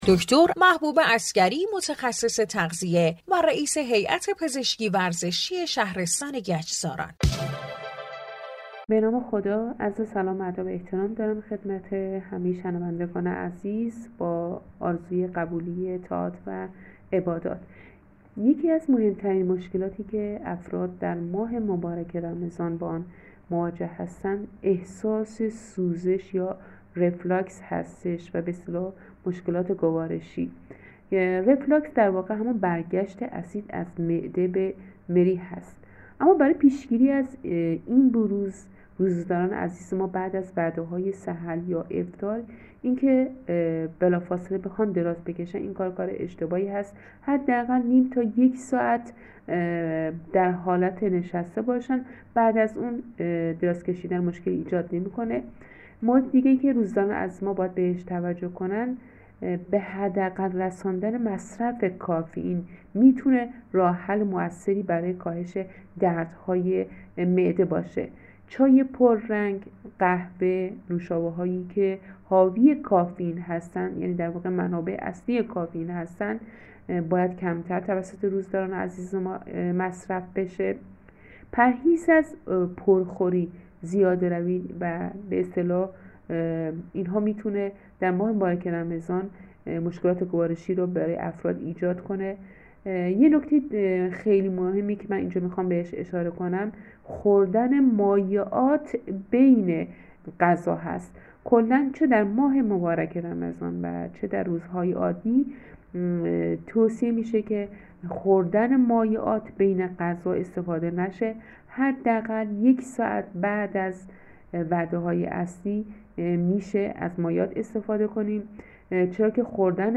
در گفت گو با برنامه بانوی ایرانی رادیو ورزش به تشریح مبحث ورزش و روزه داری پرداخت.